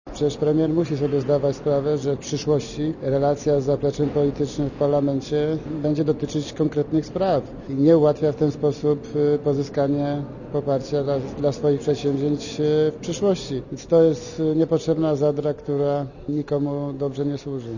Posłuchaj komentarza Marka Dyducha